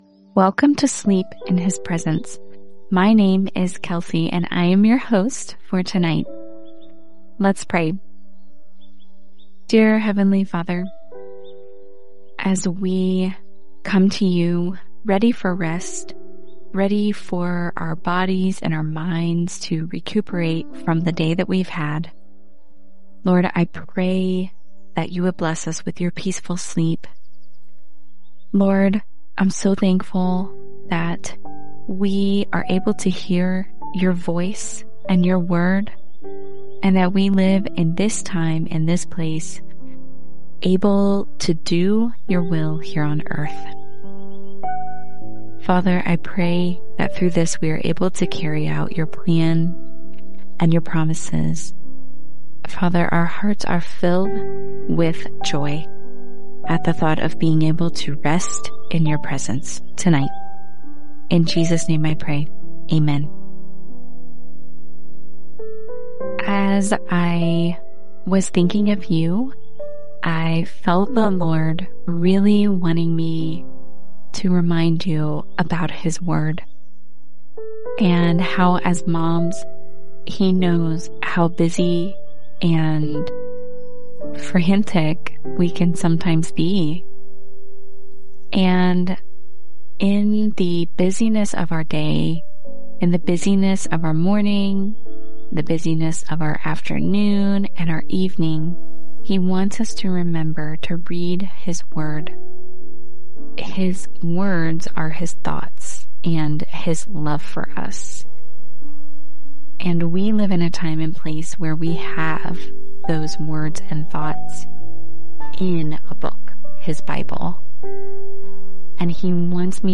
In this 6-day devotional, you’ll be gently led into the presence of God through heartfelt prayers, encouraging words, and powerful Scripture. Each night, a different host guides you to release your worries, embrace God’s love, and fall asleep knowing He is near.